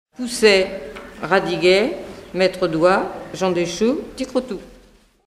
enfantine : comptine
Pièce musicale éditée